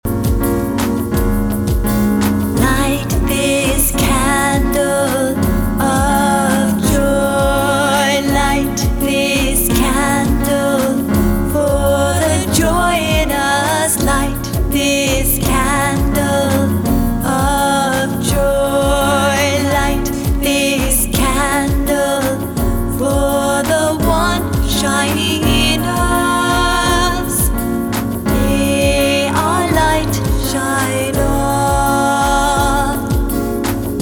soaring vocals blend in beautiful harmonies